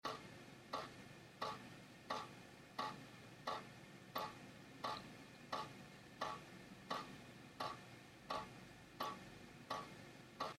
kapajici kohoutek do drezu.mp3